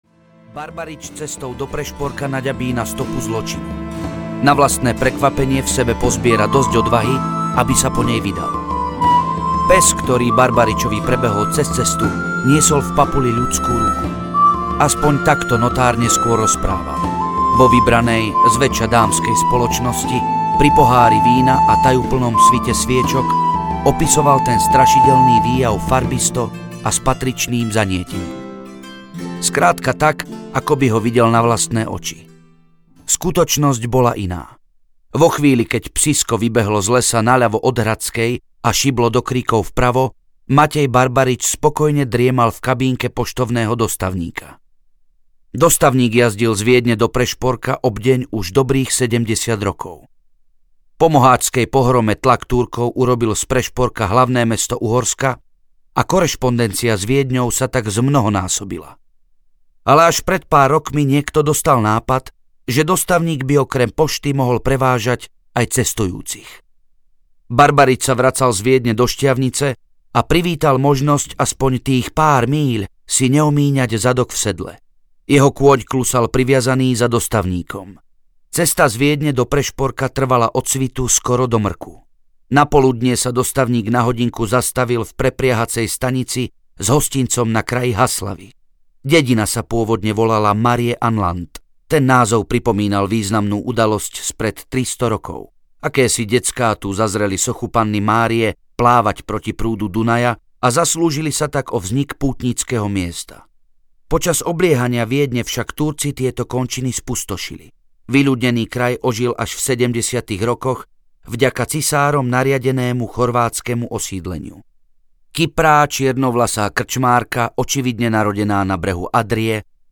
Ohnivé znamenie audiokniha
Ukázka z knihy